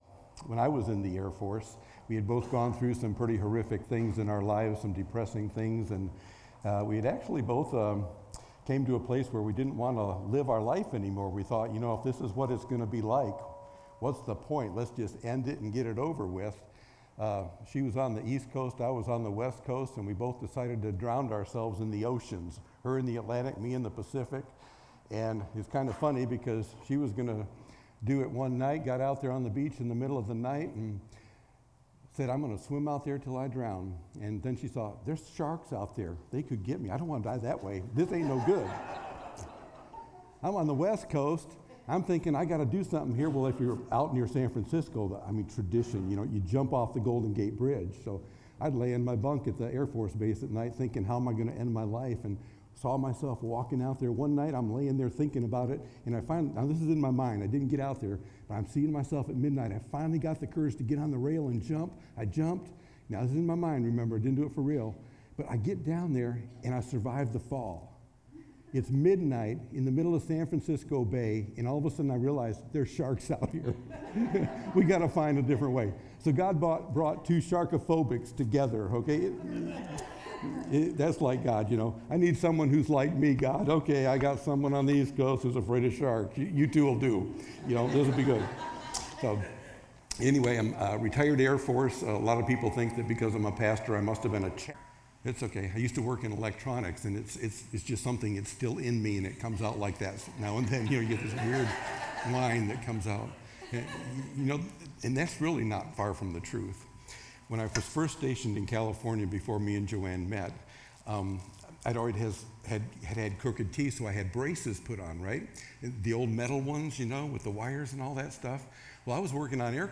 Sermon-8-04-24.mp3